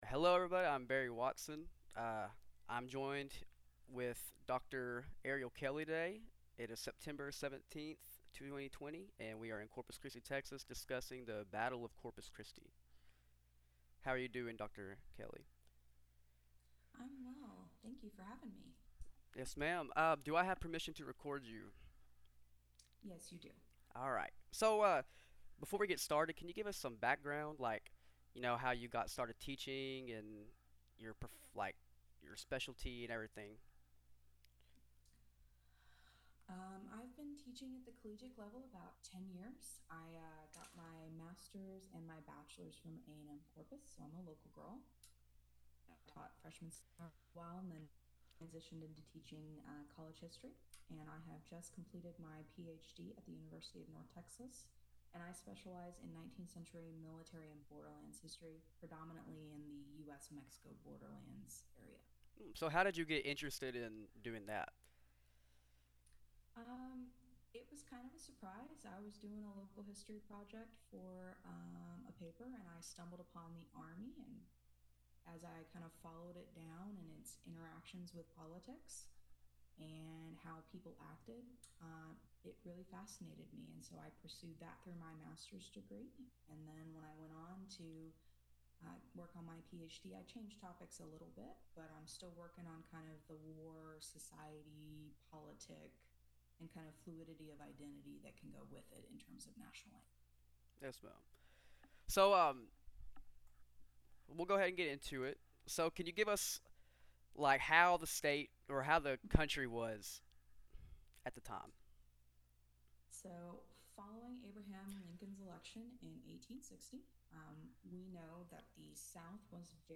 Oral History Interview
Corpus Christi, Texas, Interview conducted over video conference